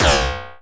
sci-fi_weapon_blaster_laser_fun_02.wav